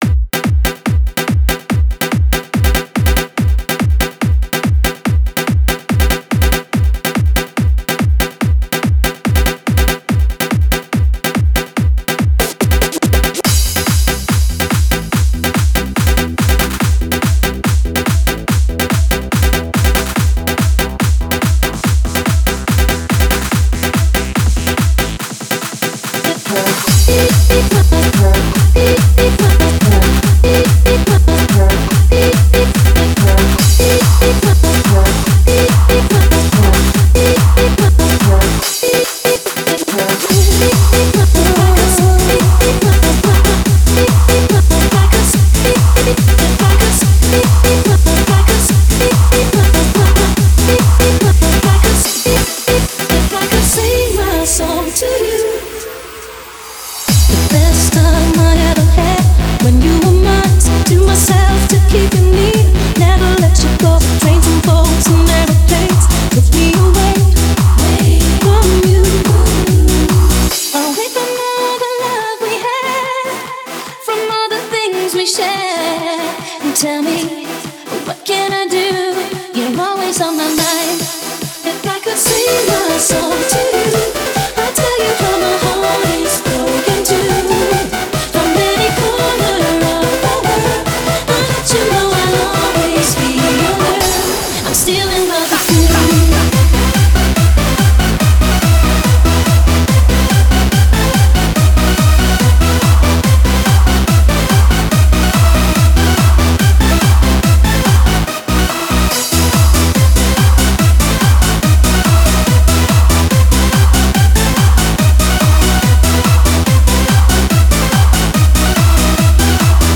Something in old style 🫡